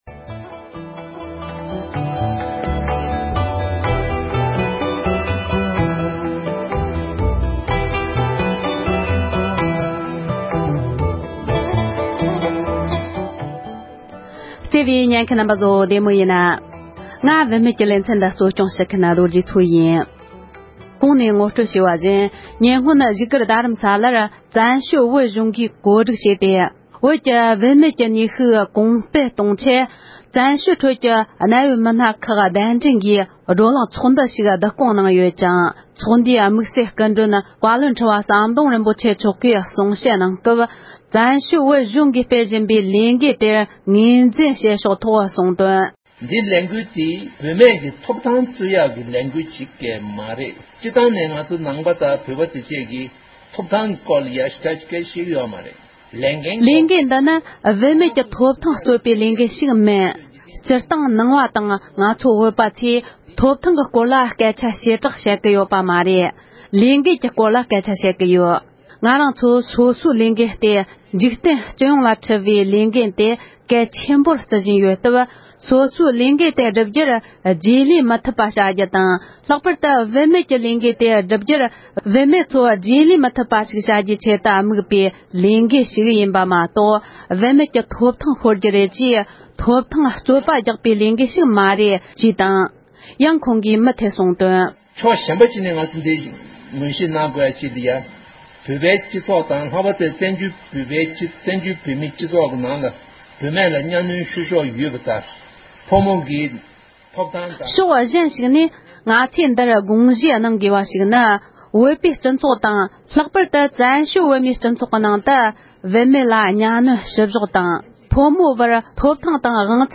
༸གོང་ས་མཆོག་དང་བཀའ་བློན་ཁྲི་པ་རྣམ་གཉིས་ནས་བུད་མེད་ནུས་ཤུགས་གོང་འཕེལ་གཏོང་ཕྱོགས་ཐོག་བསྩལ་བའི་བཀའ་སློབ།